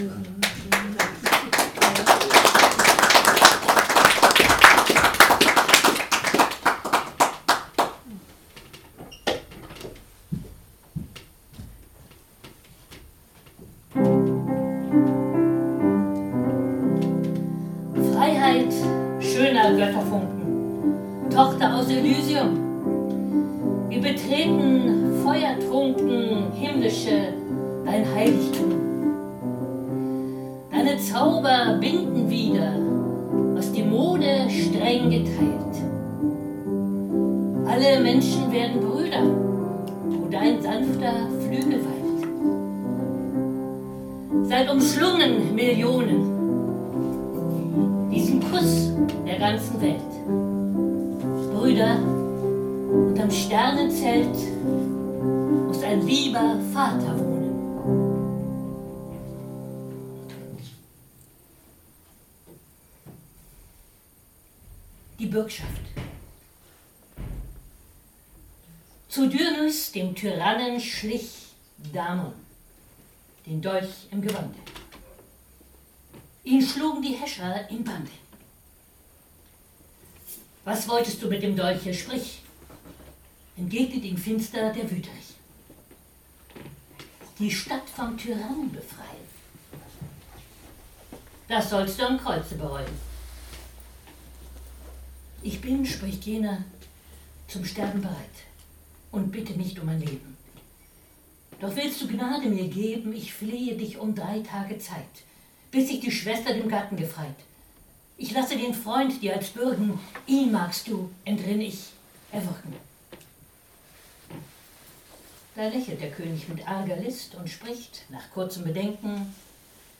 Besetzung von Sprecherin und Klavier
Lesung, Erzählung, Gedicht, Lied und Blues
Mitschnitt einer öffentlichen Veranstaltung (MP3, Audio)